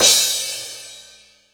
• Old School Reverb Crash Cymbal Audio Clip E Key 05.wav
Royality free crash single shot tuned to the E note. Loudest frequency: 5545Hz
old-school-reverb-crash-cymbal-audio-clip-e-key-05-uZF.wav